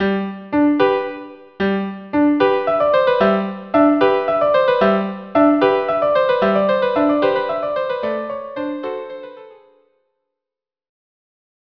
Instrumentation: Two Violins or Violin and Viola
lively and expressive